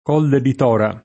k0lle di t0ra] (Lazio), Tora e Piccilli [